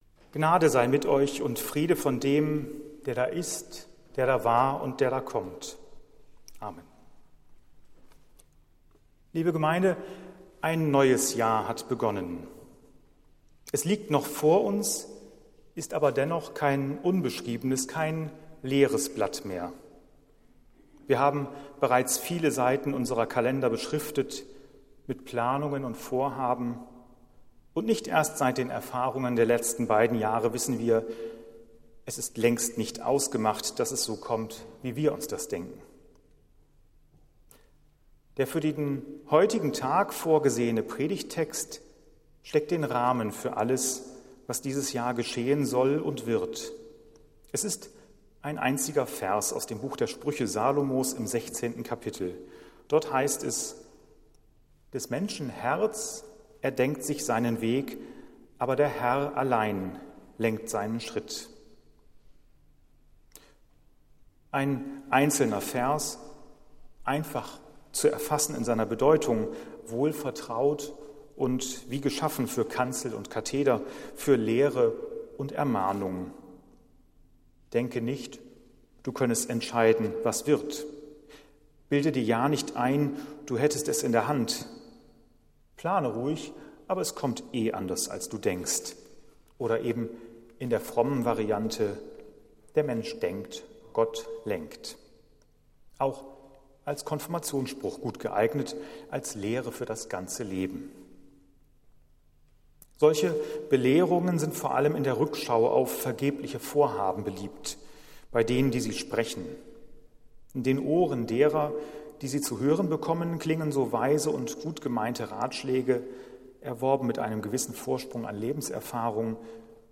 Predigt des Gottesdienstes am Neujahrstag
Wir haben uns daher in Absprache mit der Zionskirche entschlossen, die Predigten zum Nachhören anzubieten.